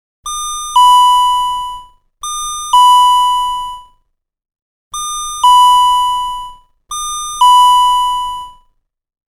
Chime -
Ding Dong Sound @ 1230/1010Hz
chime.mp3